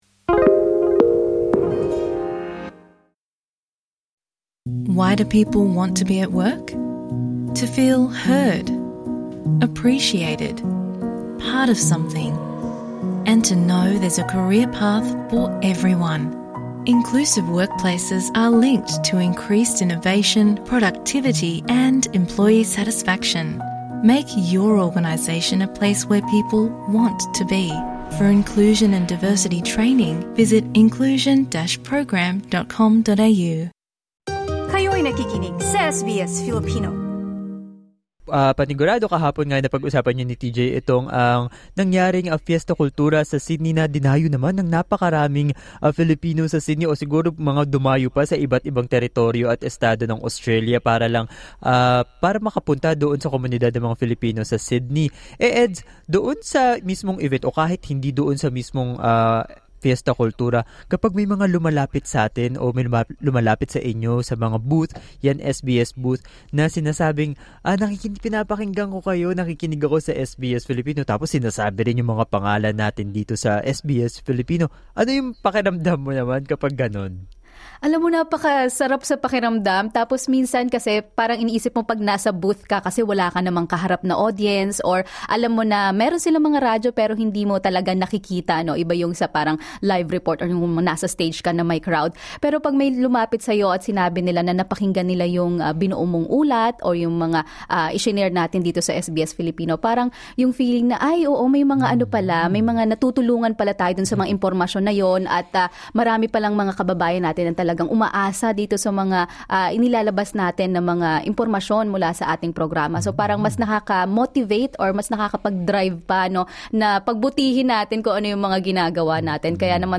Dumalo ang mga masugid na tagapakinig ng SBS Filipino sa booth ng SBS at ibinahagi kung paano sila natutulungan ng programa na maging updated sa lokal at internasyonal na balita.